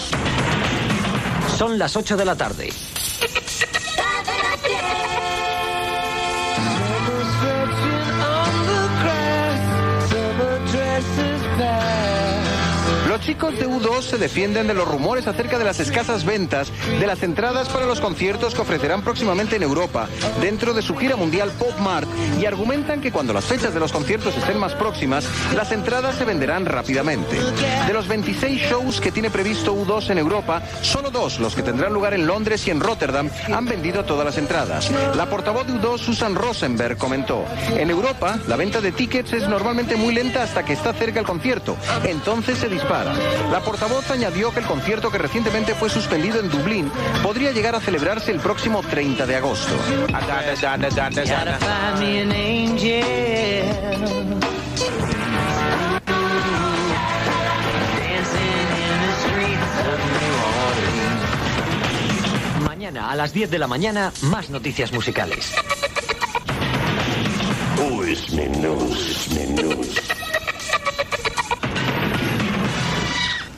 Hora, indicatiu, notícia musical sobre la gira "Pop mart" del grup U2, indicatiu
FM
Gravació realitzada a València.